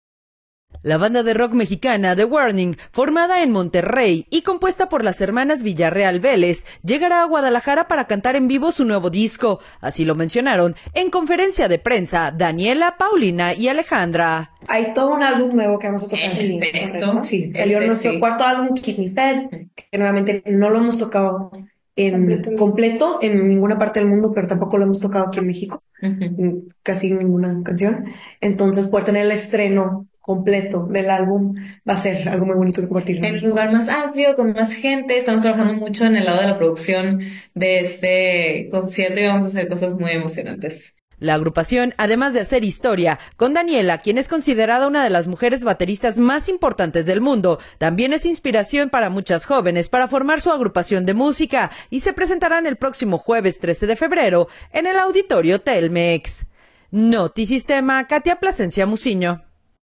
así lo mencionaron en conferencia de prensa